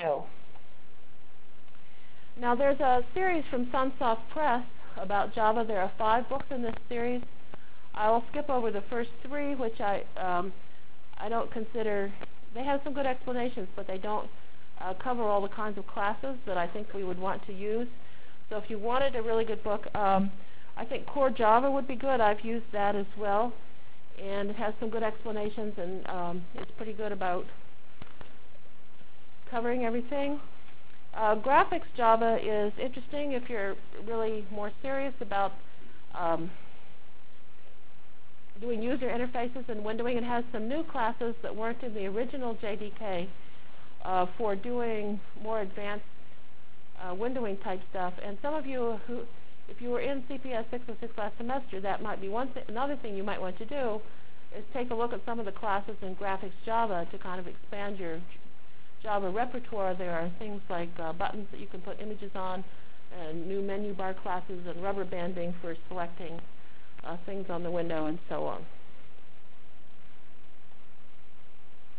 From Jan 22 Delivered Lecture for Course CPS616 -- Java Lecture 1 -- Overview CPS616 spring 1997 -- Jan 22 1997.